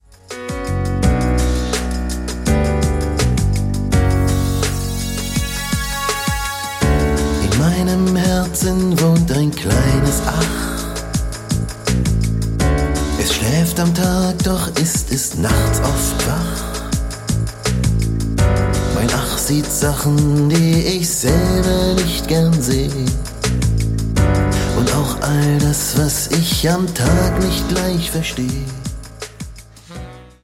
Rotzfreche Kinderlieder mit viel Berliner Luft